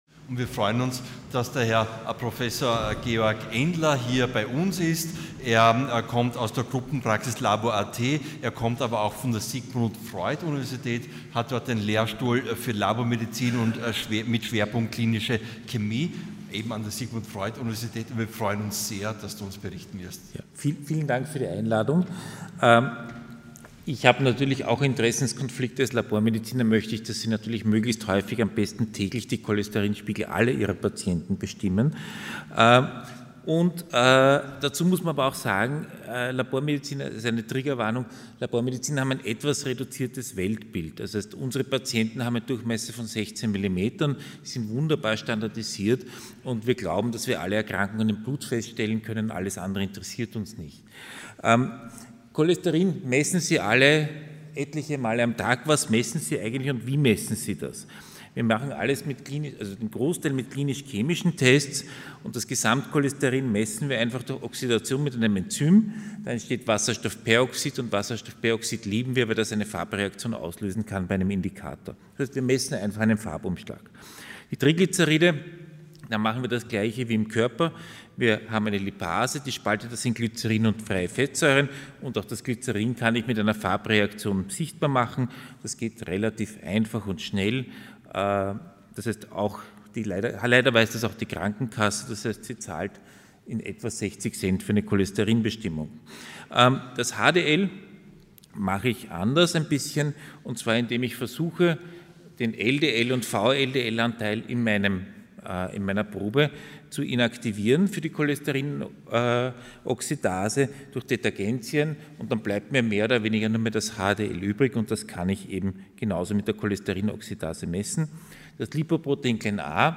Sie haben den Vortrag noch nicht angesehen oder den Test negativ beendet.
Hybridveranstaltung | Lange Nacht der Lipide in Kooperation mit der Cholesterinallianz